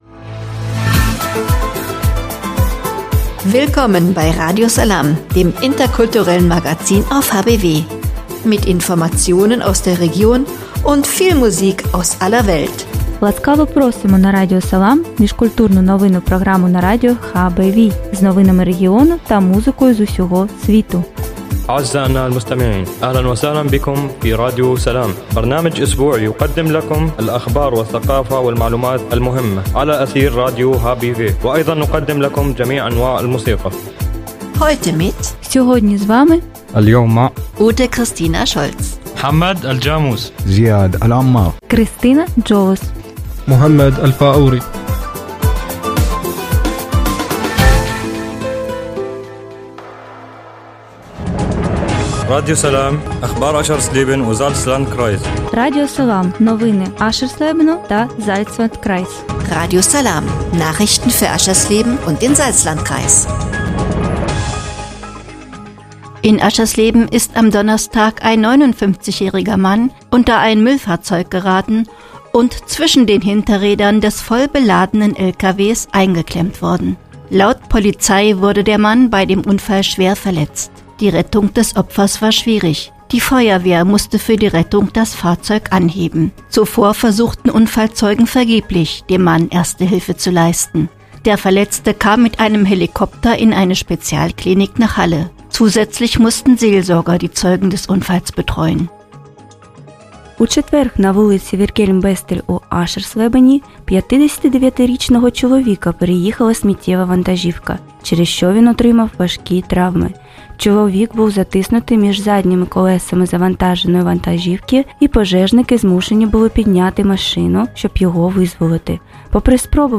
„Radio Salām“ heißt das interkulturelle Magazin auf radio hbw.